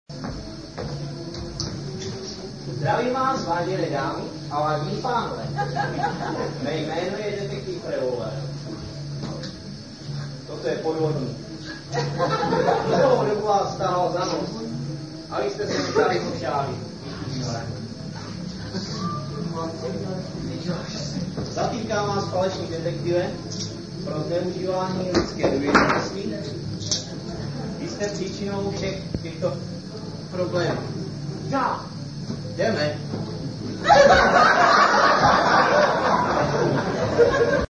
Vystoupení se konalo 29. června 1999.